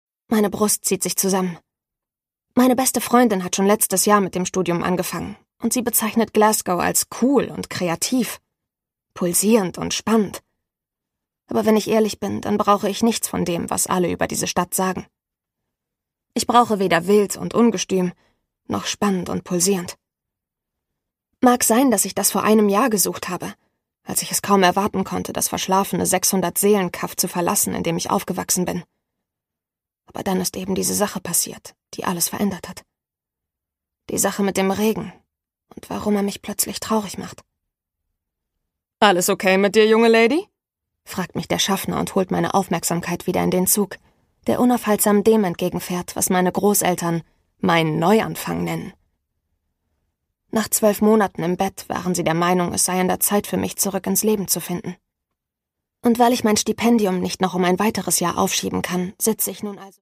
Saskia Hirschberg: Will You Hold My Hand? (Ungekürzte Lesung)
Produkttyp: Hörbuch-Download